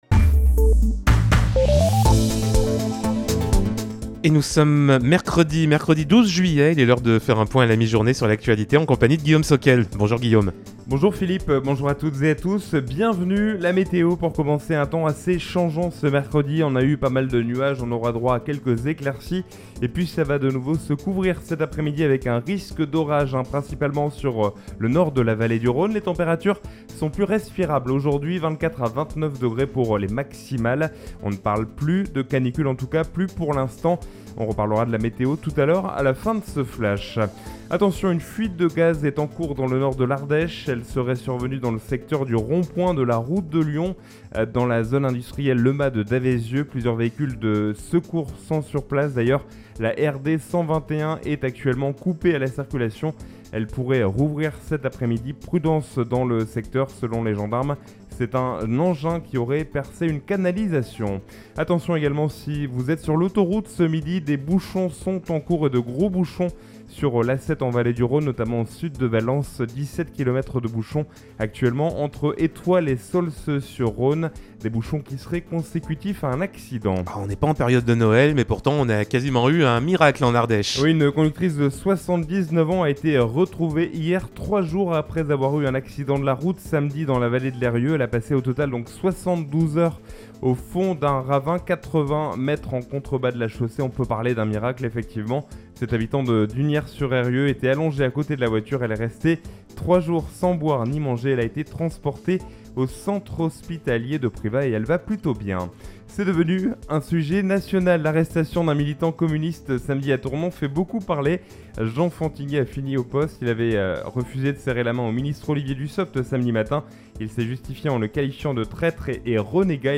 Mercredi 12 juillet : Le journal de 12h